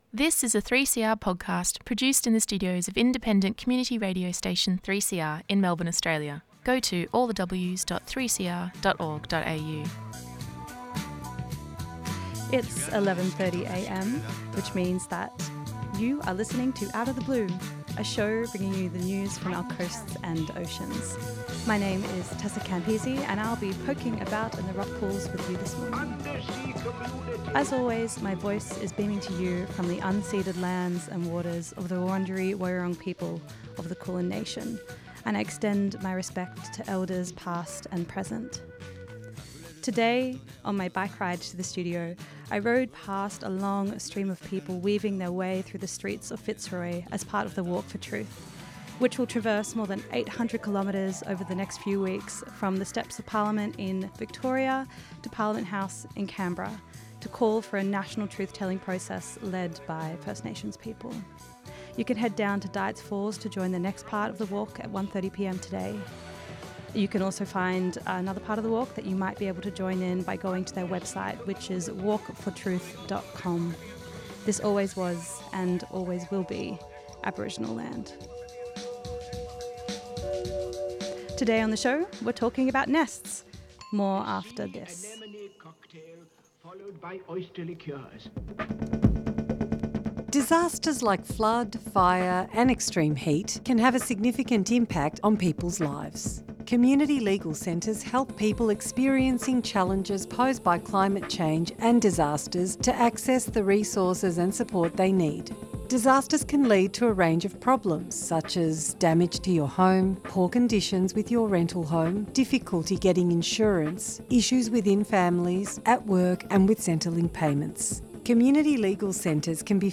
3CR Community Radio